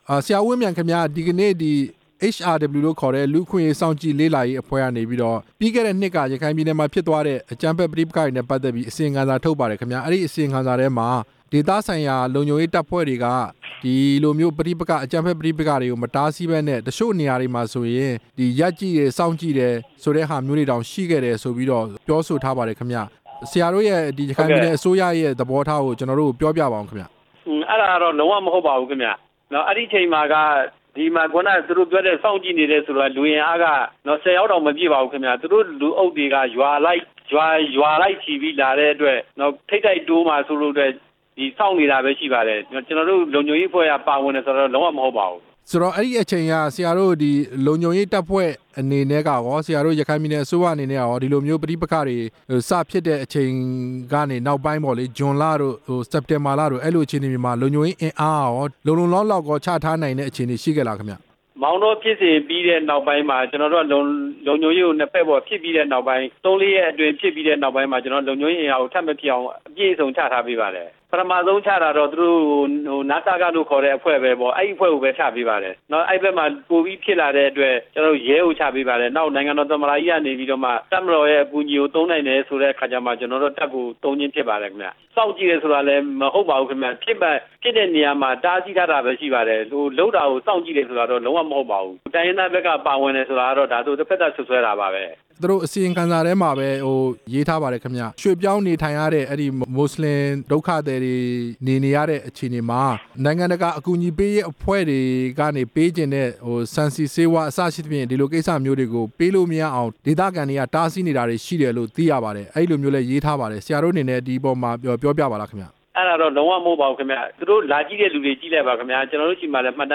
ဦးဝင်းမြိုင်နဲ့ မေးမြန်းချက်
HRW ရဲ့ အစီရင်ခံစာပါ အချက်တချို့နဲ့ ပတ်သက်ပြီး ရခိုင်ပြည်နယ်အစိုးရ ပြောရေးဆိုခွင့်ရှိသူ ဦးဝင်းမြိုင်ကို ဆက်သွယ် မေးမြန်းထားတာ နားဆင်နိုင်ပါတယ်။